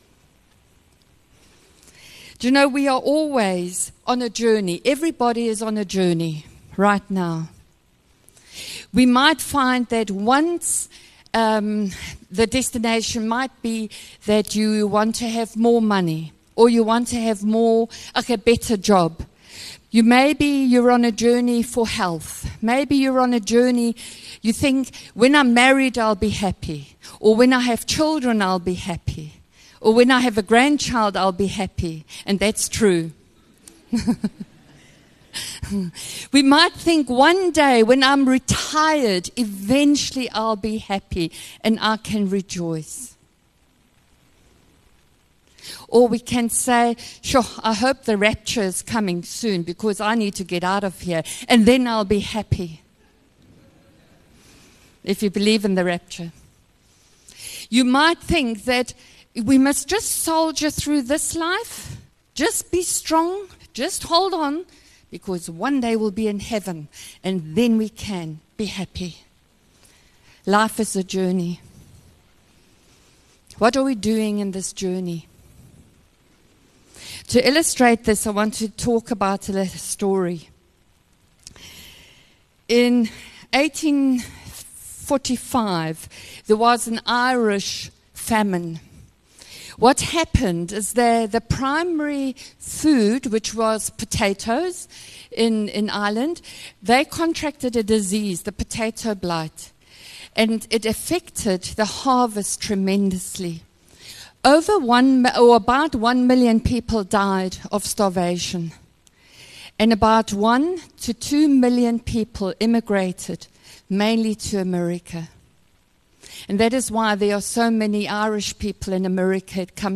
View Promo Continue JacPod Install Upper Highway Vineyard Sunday messages 25 May It is finished - Telestai 33 MIN Download (15.5 MB) Sunday message 25 May 2025.